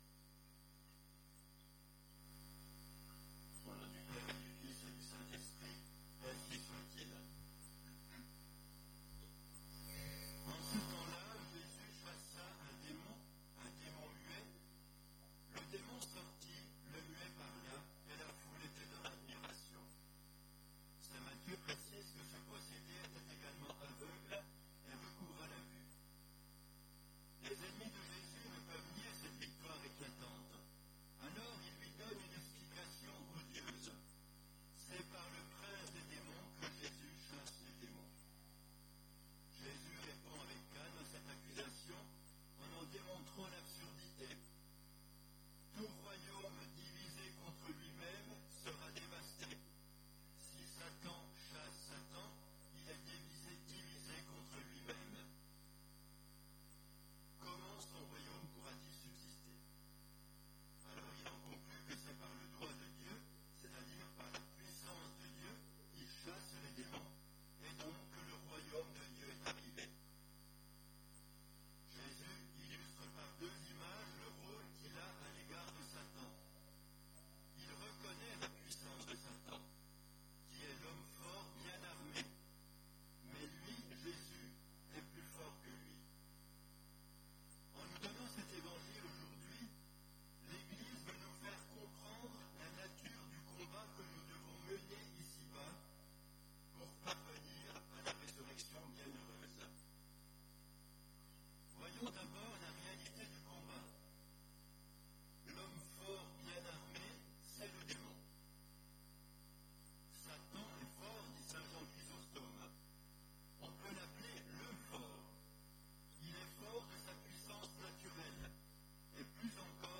Occasion: Troisième Dimanche de Carême
Type: Sermons